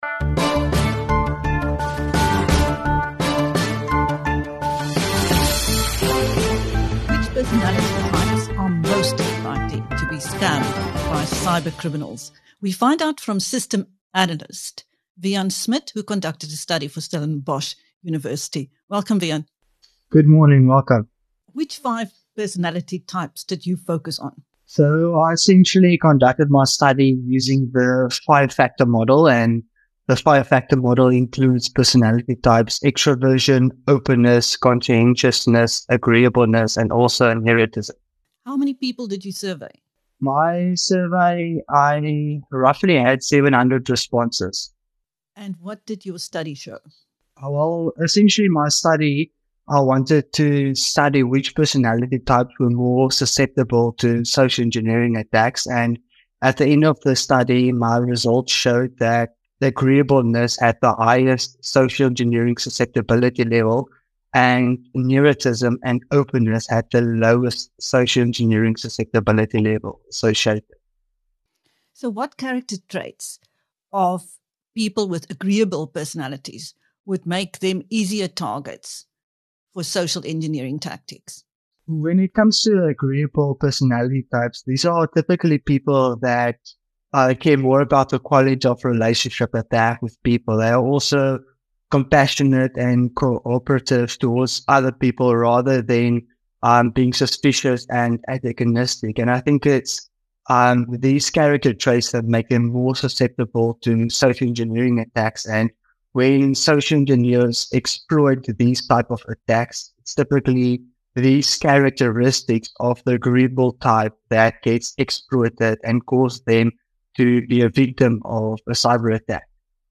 In this interview with BizNews